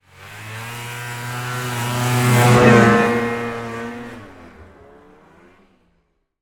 На этой странице собраны звуки снегохода: рев мотора, скрип снега под гусеницами, свист ветра на скорости.
Сноумобиль промчался быстро рядом